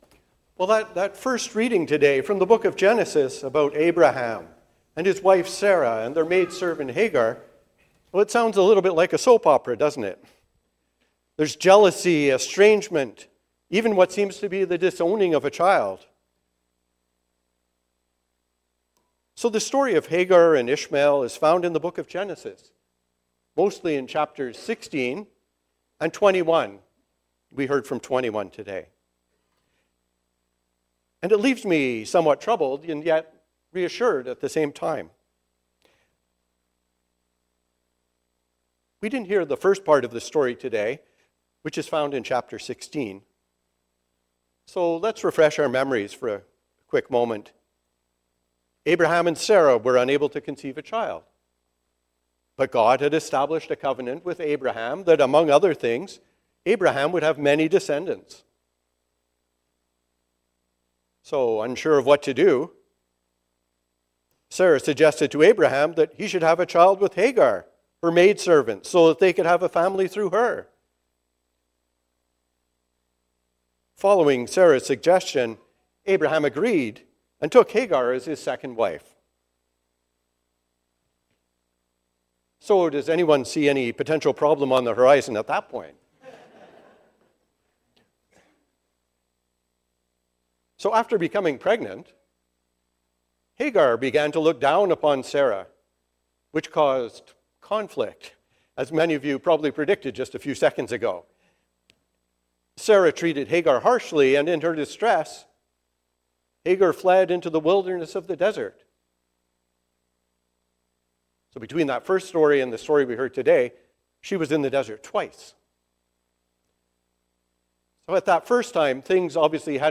A Sermon for the Fourth Sunday after Pentecost